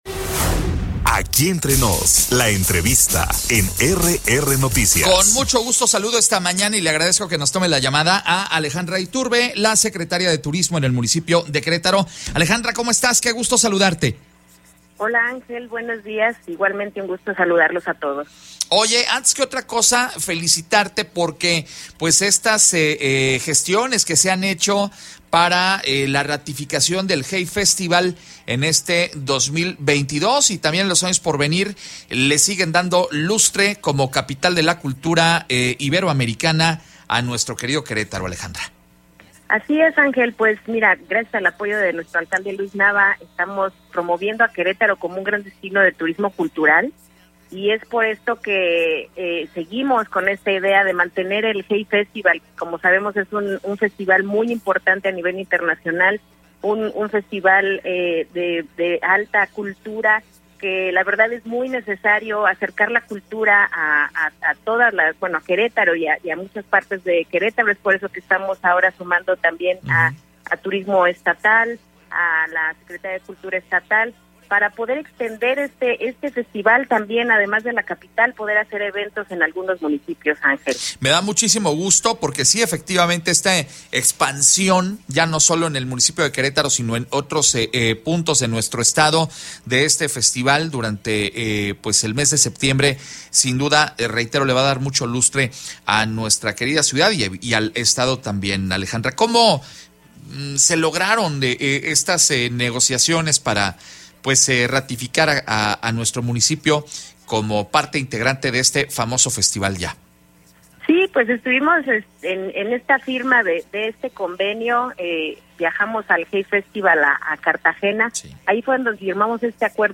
EntrevistasOpiniónPodcast